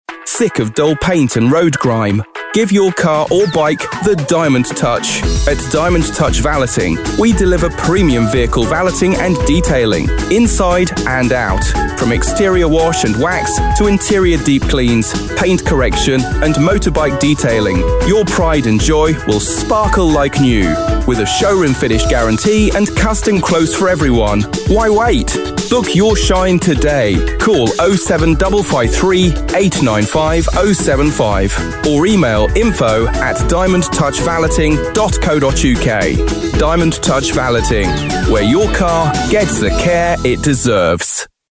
Listen to our North Derbyshire Radio Advert Here
DIAMOND-TOUCH-COMMERCIAL-COPY-1.mp3